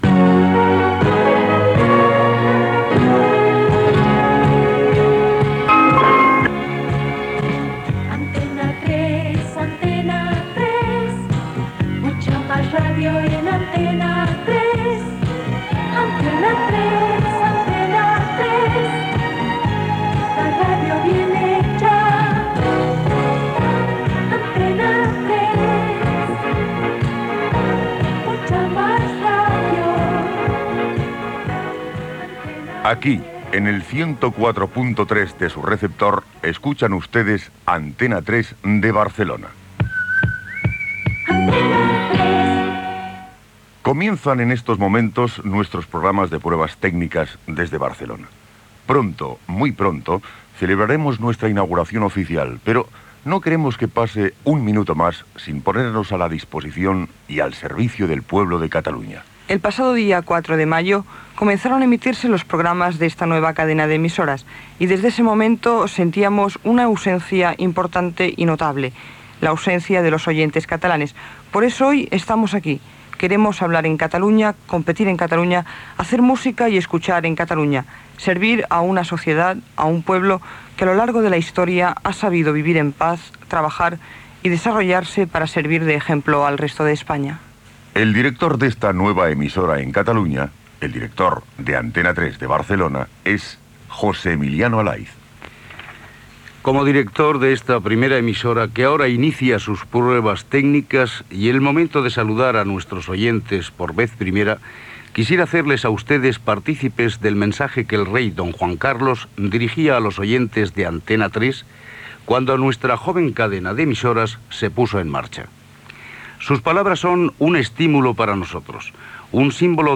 Sintonia, presentació, missatge del Rei Juan Carlos, himnes
Data emissió 1982-12-10 Banda FM Localitat Barcelona Comarca Barcelonès Durada enregistrament 10:47 Notes Emissió inaugural, després de les fetes uns dies abans a Sonimag.